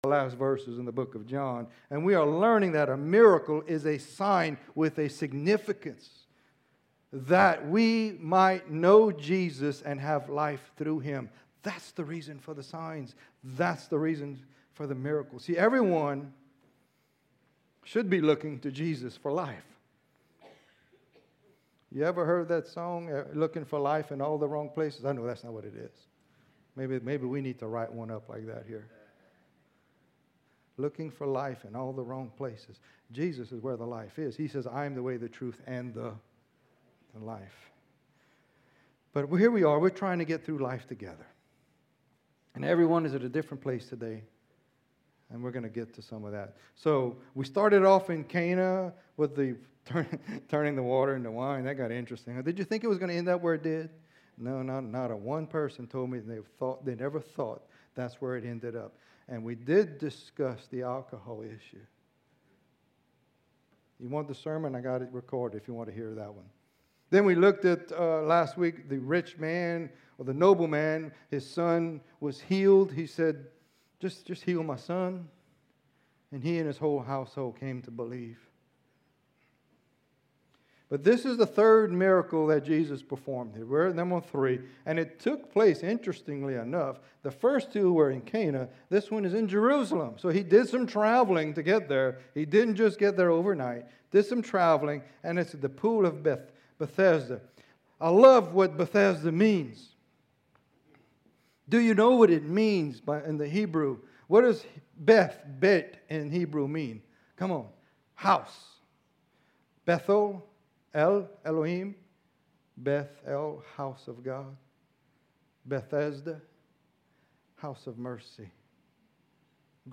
Sermons - Springhill Baptist Church